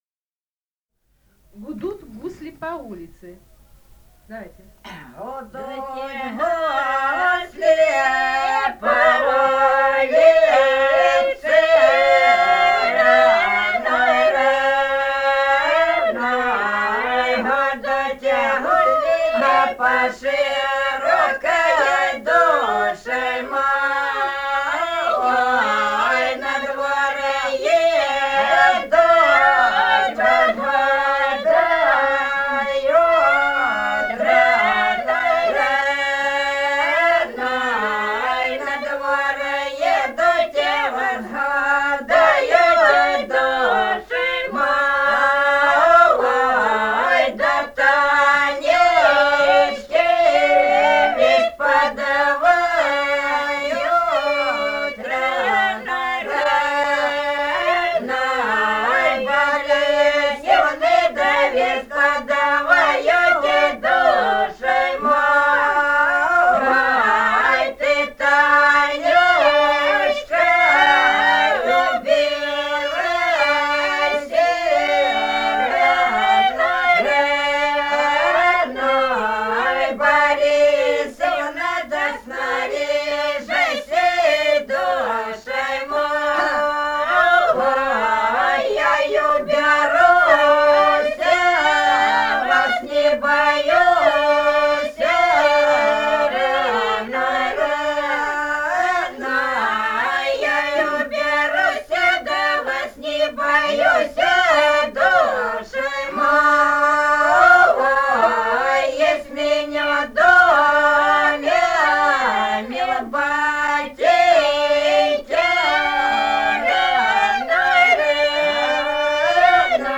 Этномузыкологические исследования и полевые материалы
Ростовская область, г. Белая Калитва, 1966 г. И0941-05